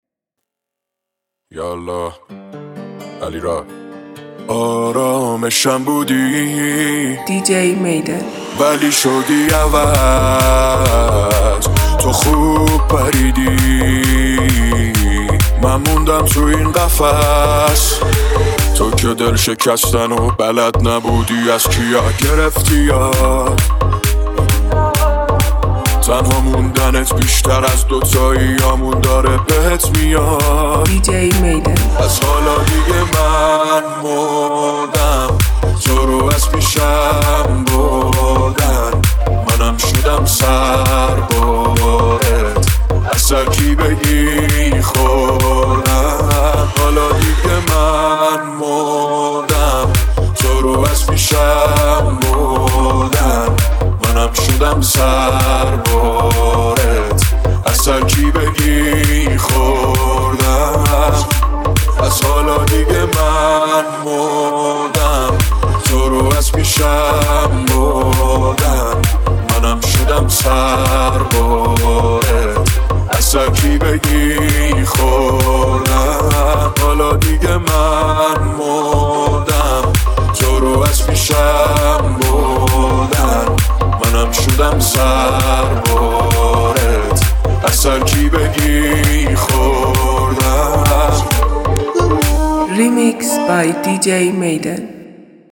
بیس دار تند